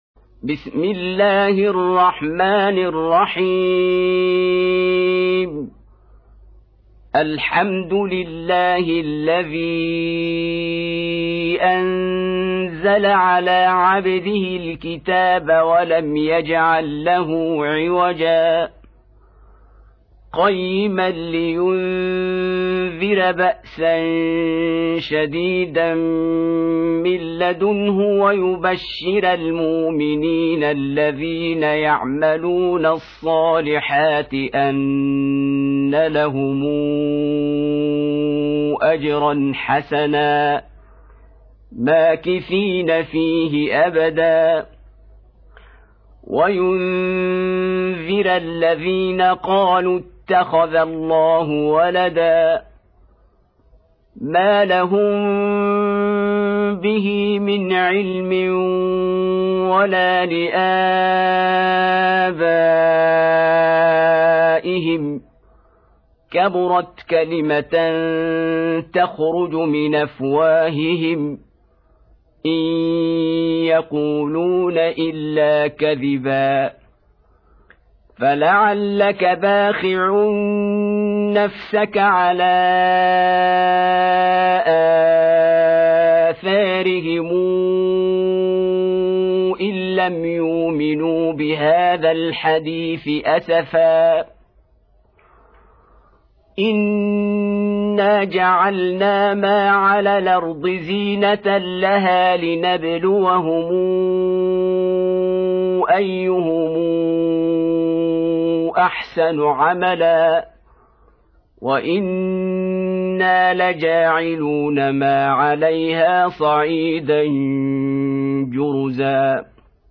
18. Surah Al-Kahf سورة الكهف Audio Quran Tarteel Recitation
Surah Repeating تكرار السورة Download Surah حمّل السورة Reciting Murattalah Audio for 18.